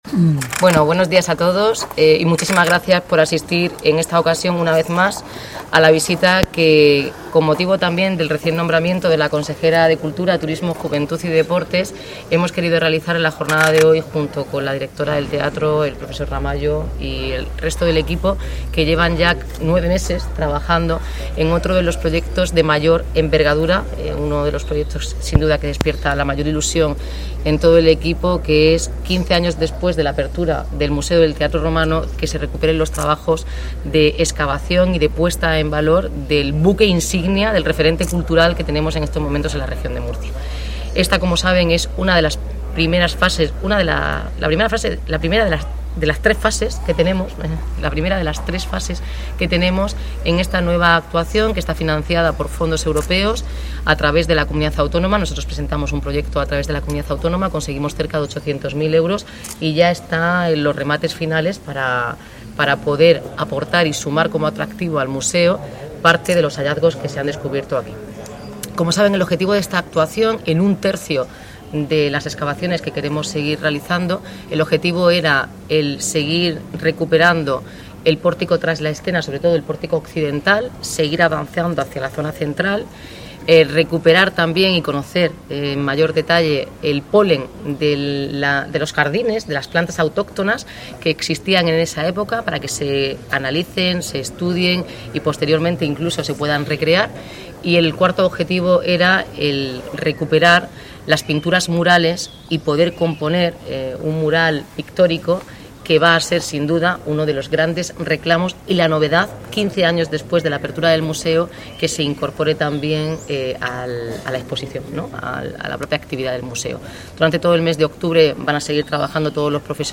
Enlace a Declaraciones de la alcaldesa Noelia Arroyo, la consejera de Cultura, Carmen Conesa